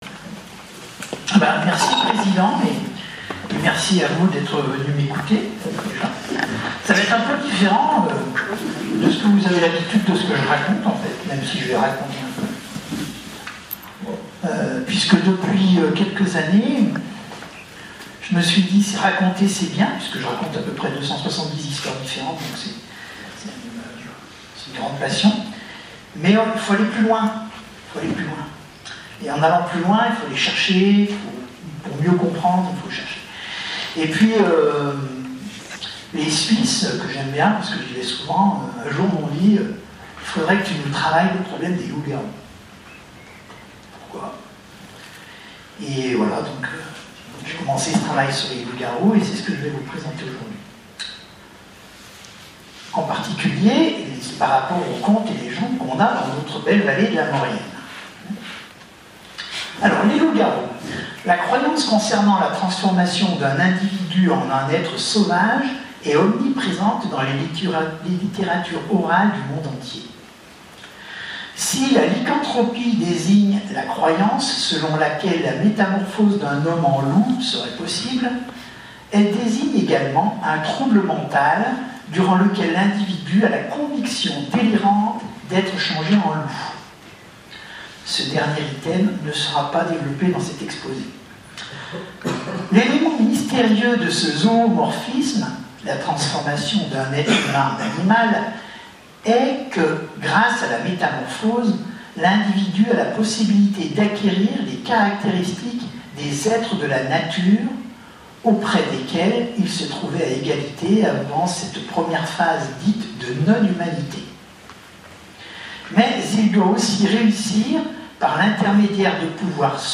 Enregistrement audio de la conférence du 12 juin 2024 : (1:10:08)